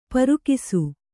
♪ parukisu